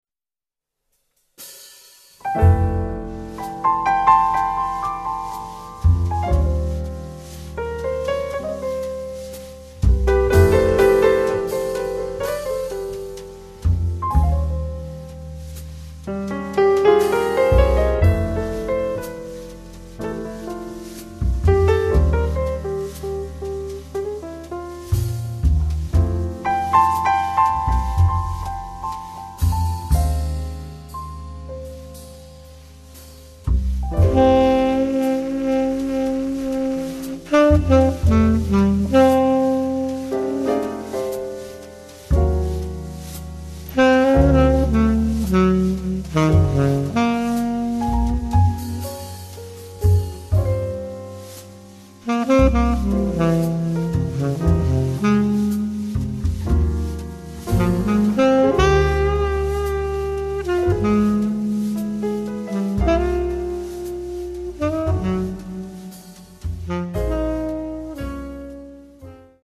sax tenore
piano
contrabbasso
batteria
una struggente ballads